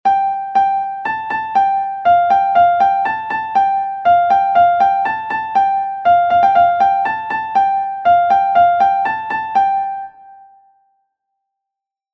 Fış Fış Kayıkçı Uzun Bağlama Notaları Sözleri - Notaları - Yöresi - Hikayesi - Söyleyeni - MP3 İndir - İzle - Dinle - Neden Yazılmış - Kim Söylüyor - Kim Yazmış | Söz ve Nota
fis_fis_kayikci_uzun_baglama_notalari.mp3